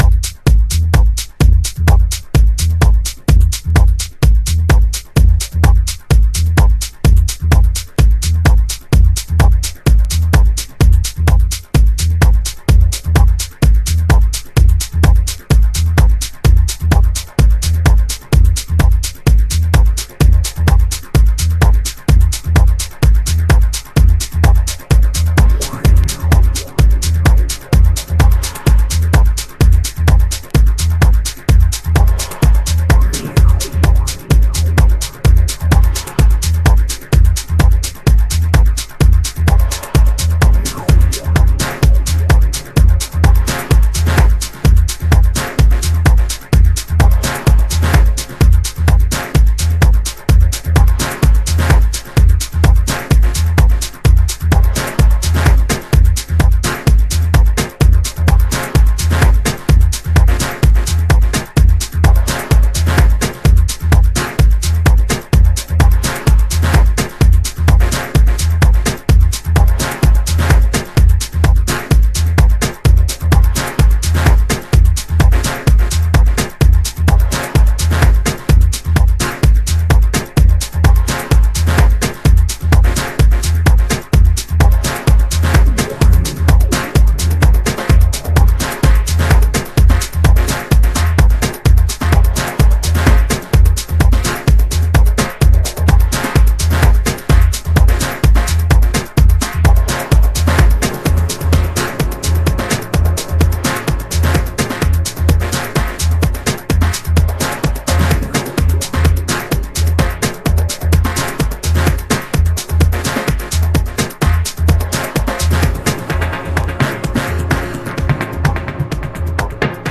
攻めのシンセウェーヴが心地良いダブテクノ。